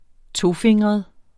Udtale [ -ˌfeŋˀʁʌð ]